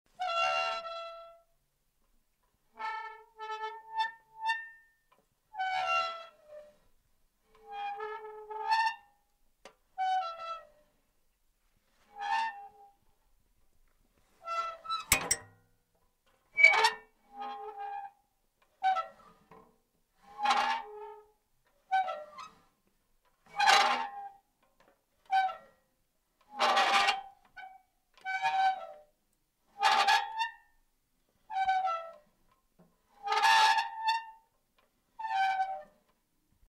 Звуки калитки
Железная калитка скрипит и качается